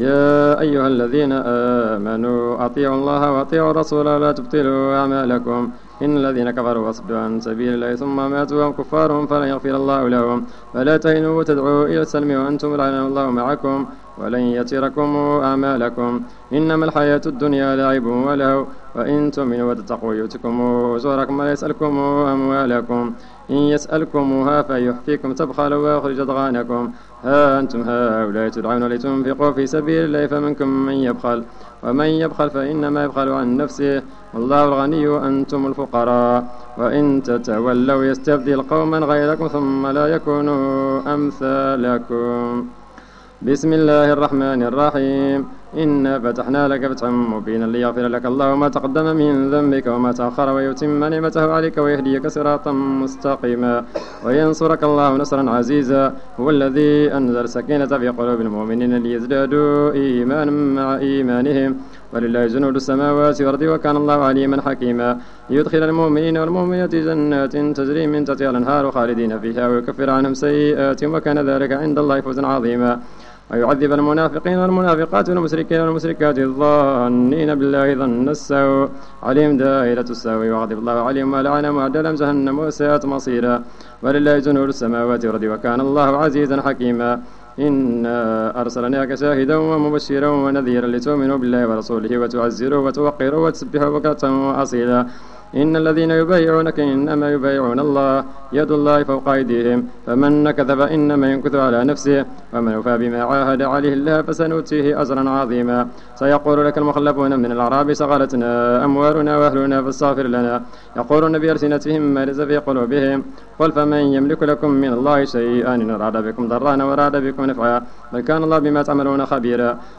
صلاة التراويح ليوم 25 رمضان 1431 بمسجد ابي بكر الصديق ف الزو
صلاة رقم 03 ليوم 25 رمضان 1431 الموافق سبتمبر 2010